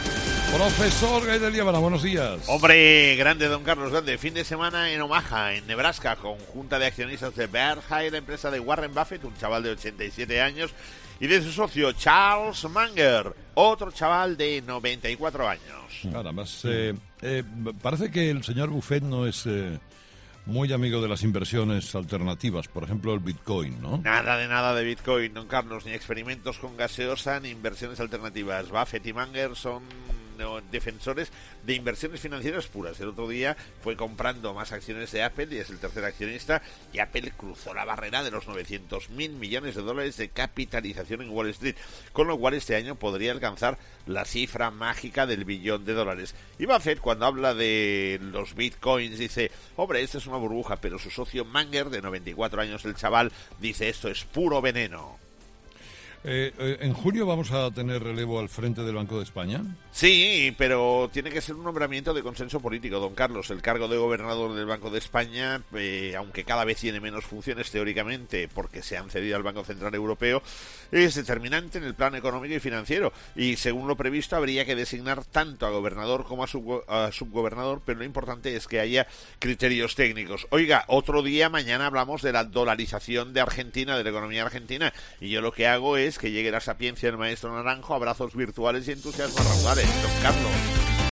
Escucha ahora 'Gay de Liébana: “El cargo de gobernador del BdE sigue siendo determinante en el plano económico y financiero”', emitido el martes 8 nde mayo de 2018, en ‘Herrera en COPE’